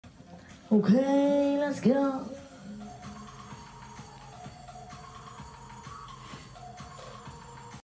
Kermis geluid Aftellen Nederlands
Categorie: Geluidseffecten
kermis geluiden, geluidseffecten
kermis-geluid-oke-lets-go-kermis-nl-www_tiengdong_com.mp3